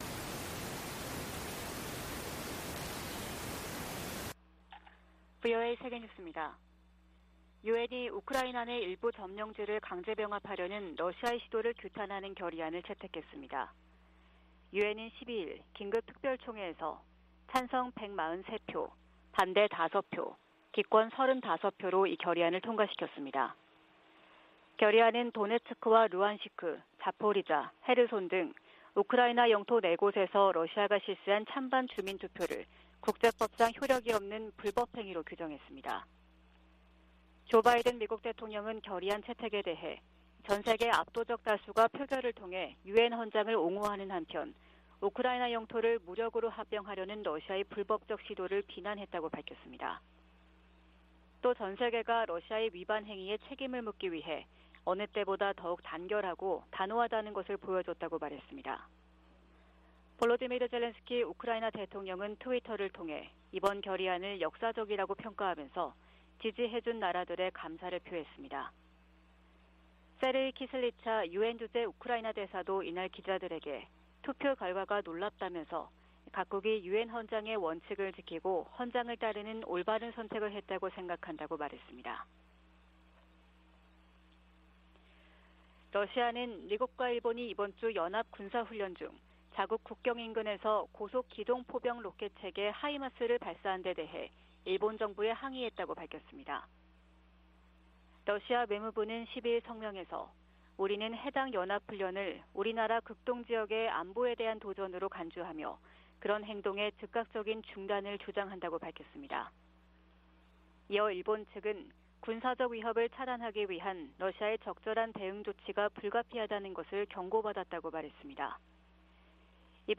VOA 한국어 '출발 뉴스 쇼', 2022년 10월 14일 방송입니다. 북한이 핵운용 장거리 순항 미사일을 시험발사했습니다.